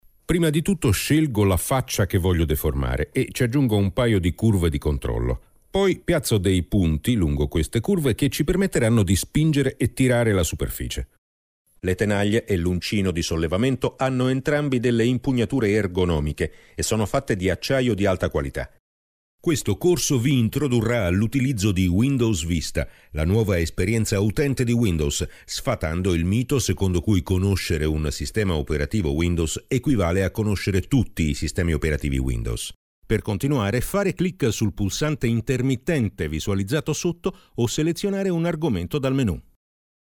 I'm a native Italian voice talent, with a solid background in radio-tv journalism and documentary making, and a real italian regional accent-free voice: mid/low range, 30s to 50s, informative, elegant, believable, professional, yet warm, friendly, seductive when needed.
I provide studio-quality recordings with .wav or .mp3 fast, reliable, safe online delivery.
Sprecher italienisch. Middle age deep voice
Sprechprobe: eLearning (Muttersprache):